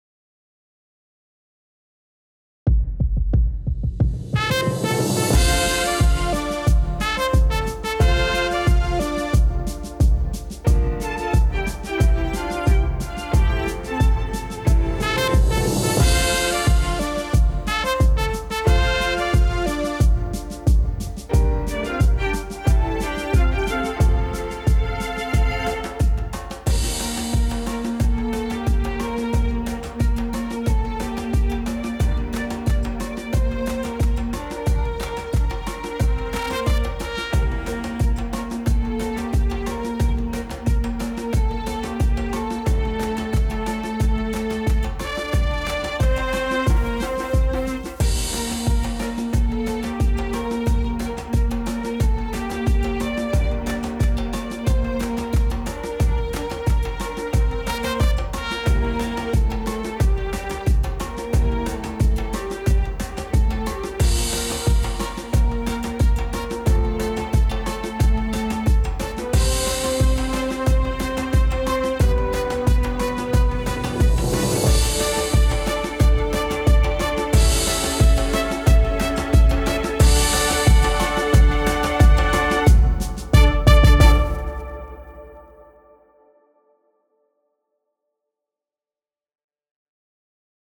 BGM
インストゥルメンタルショート明るい